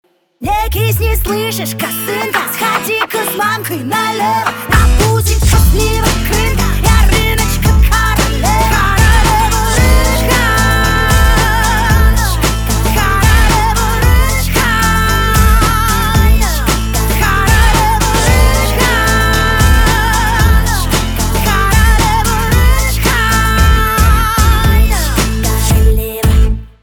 поп
гитара , барабаны